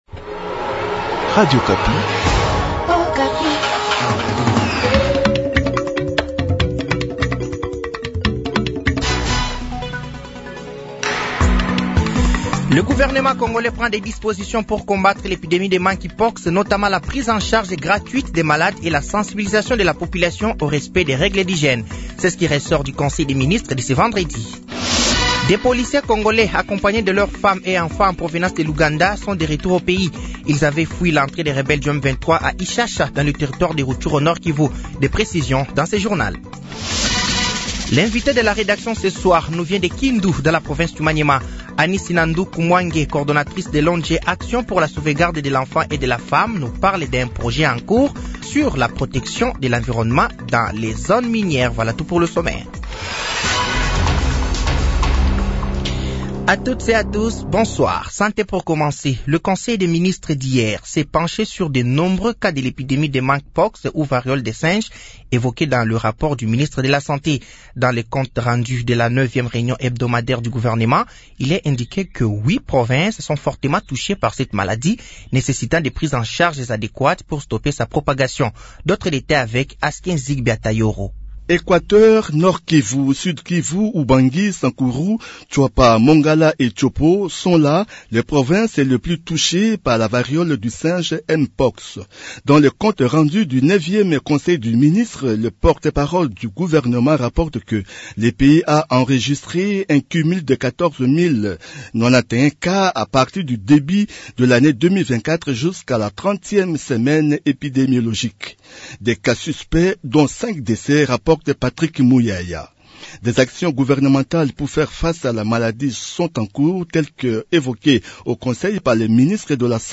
Journal Soir
Journal français de 18h de ce samedi 17 août 2024